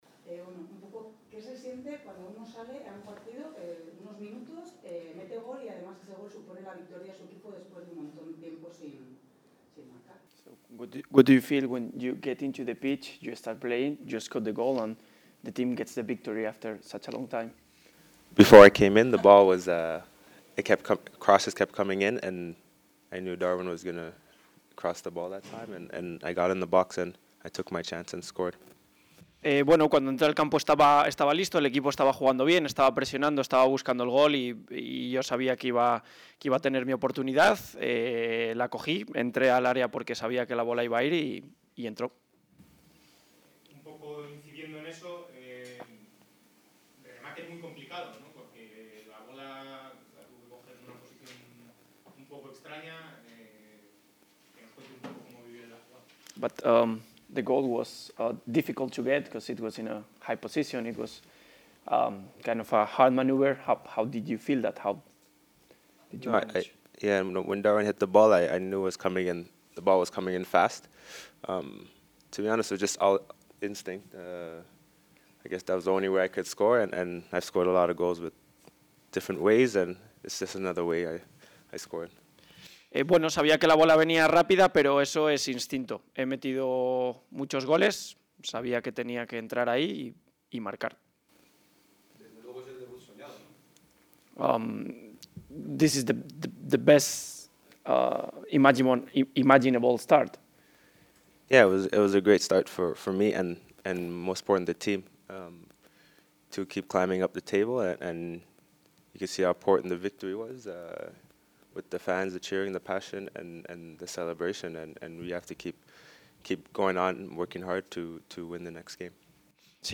El directivo arrancó su comparecencia con las condolencias a Narváez por el reciente fallecimiento de su padre, y agradeciendo a todo el personal del Club su implicación en esta ventana de incorporaciones. Sobre las incorporaciones, subrayó los perfiles de Machís, Larin, Amallah y Honga, todos ellos internacionales y tres de ellos que han jugado en la pasada Copa del Mundo; y explicó las salidas de Feddal (rescisión) y las cesiones de Narváez, Guardiola y Weissman, a las que en principio se sumará la de Malsa, sobre quien hay acuerdo, todas ellas con opción de compra.